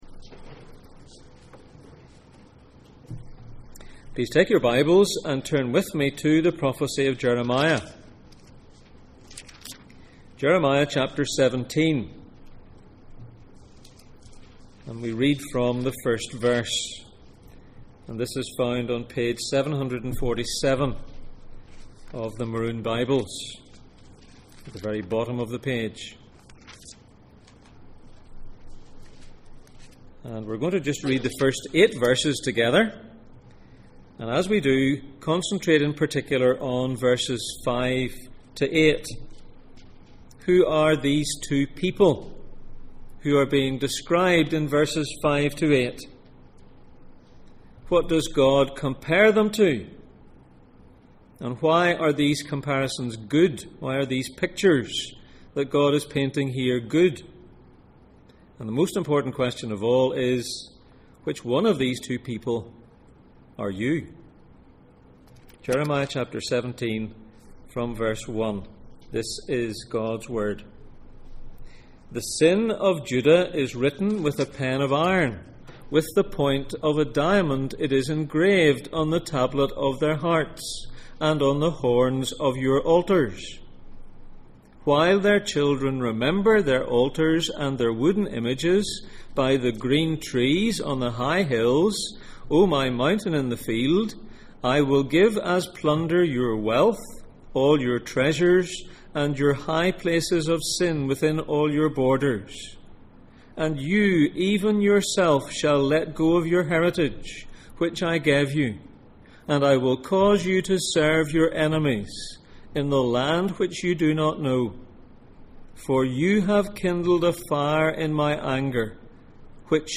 Passage: Jeremiah 17:1-8, Deuteronomy 27:11-13, Deuteronomy 27:26-28:2 Service Type: Sunday Morning